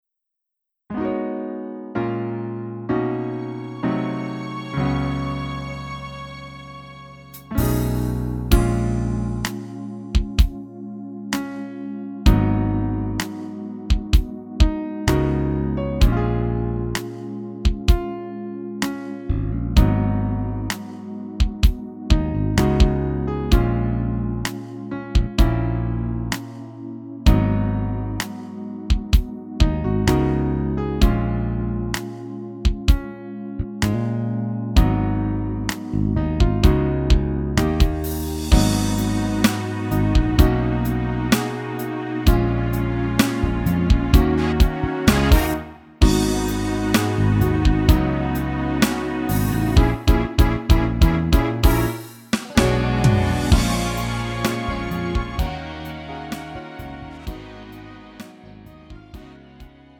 음정 원키 3:23
장르 가요 구분 Lite MR
Lite MR은 저렴한 가격에 간단한 연습이나 취미용으로 활용할 수 있는 가벼운 반주입니다.